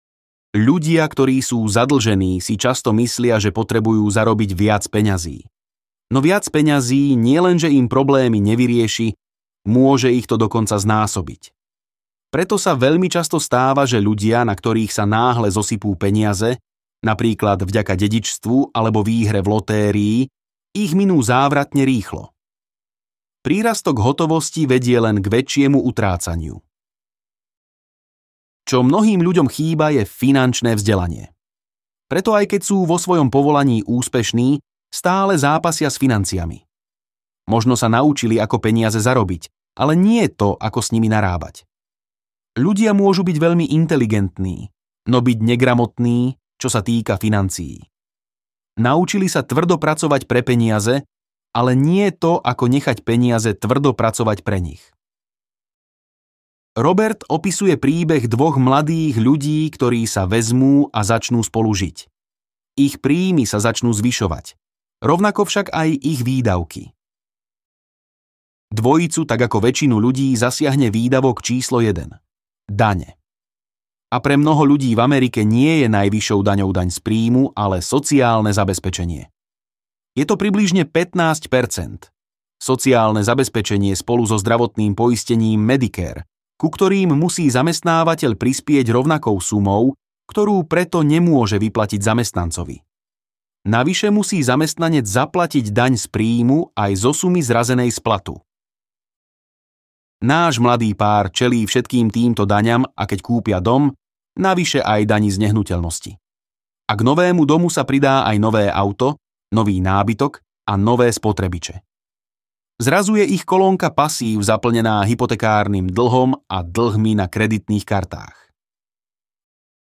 Bohatý otec, chudobný otec audiokniha
Ukázka z knihy